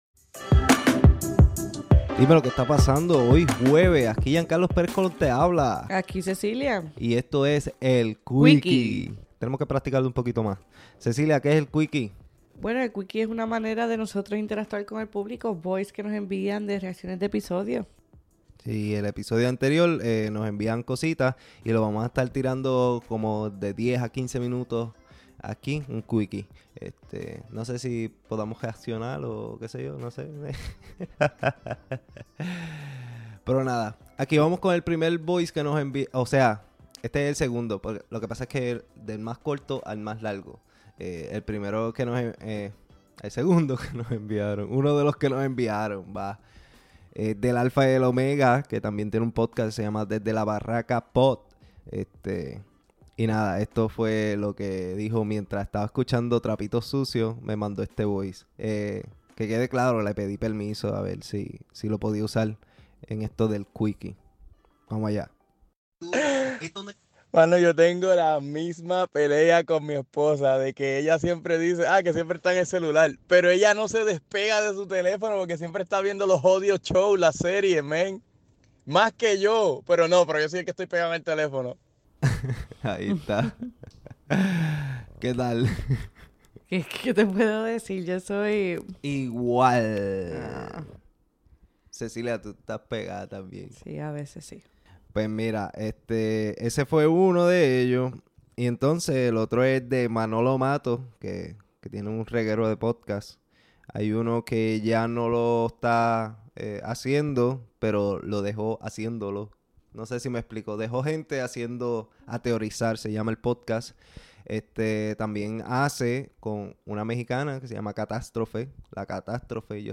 En esta sección nos dedicaremos a implementar audios que los oyentes de "Trapitos Sucios" nos envíen. Dentro de unos 10 a 15 min. compartiremos con ustedes lo que piensen los que nos siguen, apúntate para el próximo quikie.